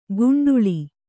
♪ guṇḍuḷi